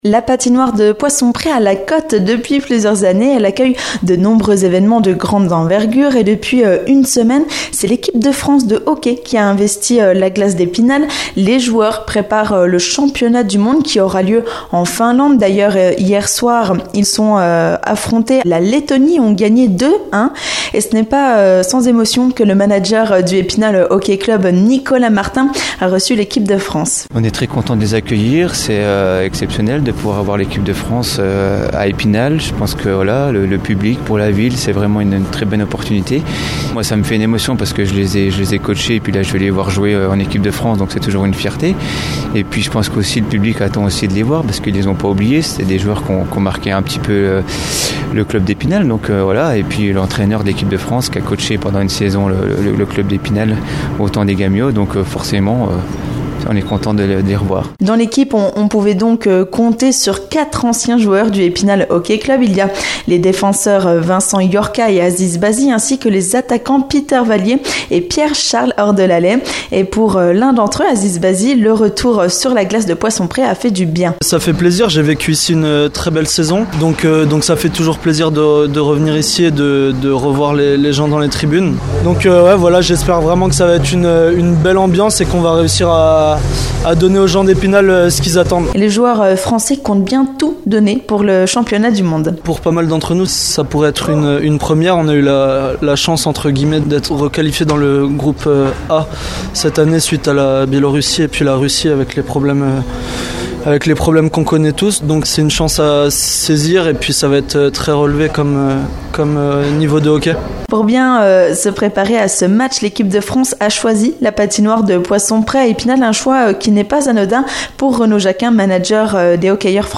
Retrouvez les réactions de